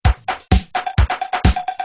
Free MP3 LinnDrum - LM1 & LM2 - Loops 4